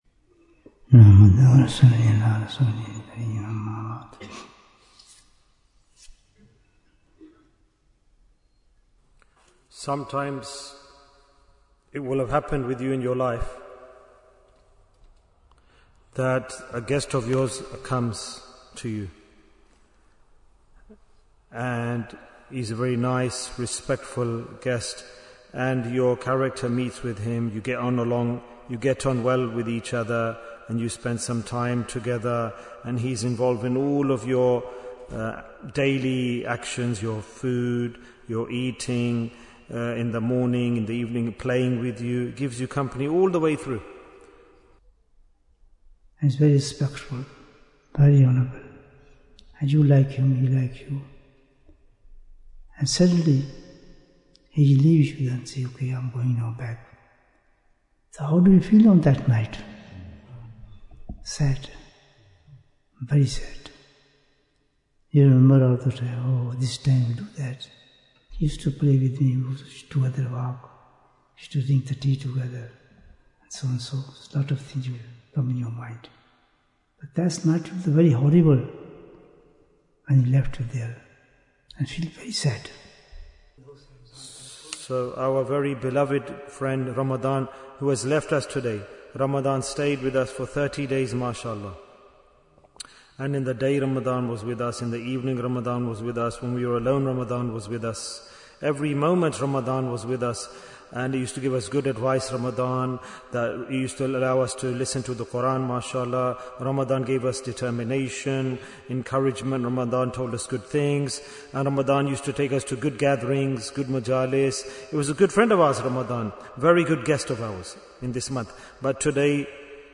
The Night of Eid Bayan, 73 minutes19th March, 2026